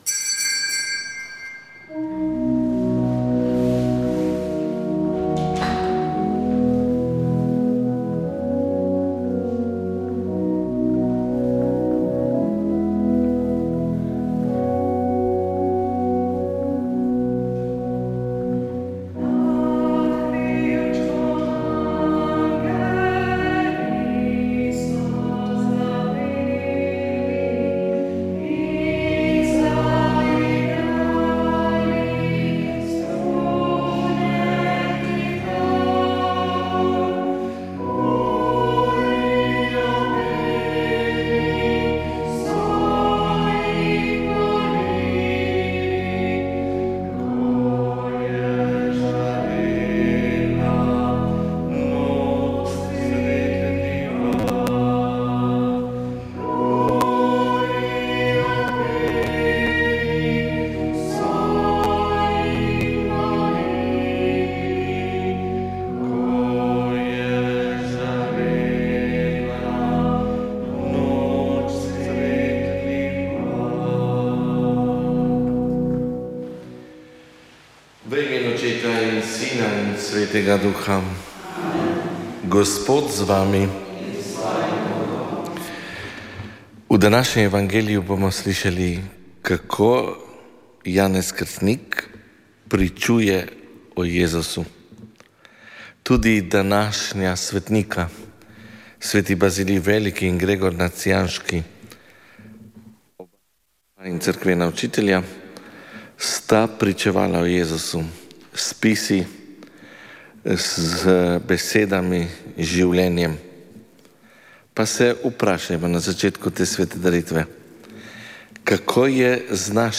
Sv. maša iz cerkve sv. Marka na Markovcu v Kopru 30. 12.